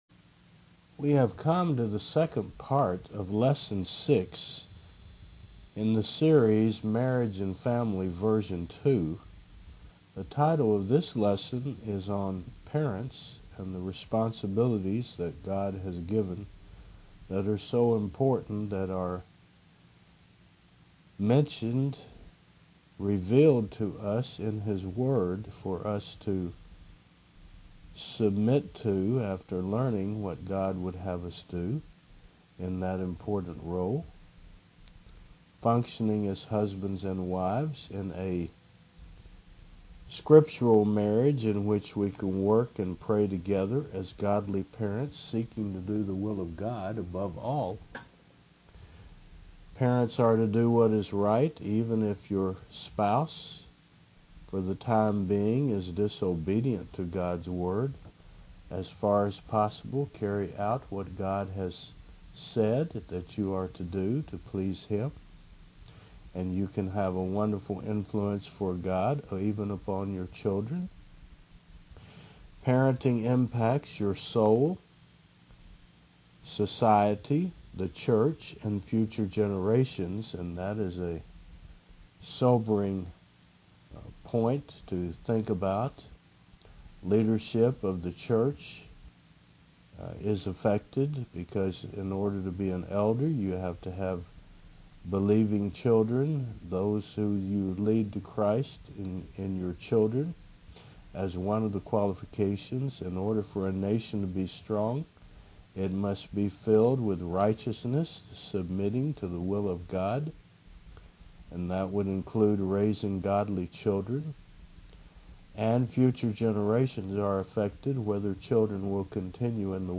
Service Type: Thu 10 AM